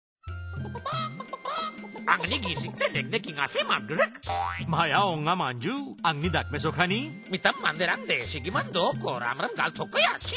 This is fourth in the series of five Radio PSA and address backyard farmers and their families. It also uses a performer and a rooster puppet as a creative medium to alert families to poultry diseases and instill safe poultry behaviours.
Radio PSA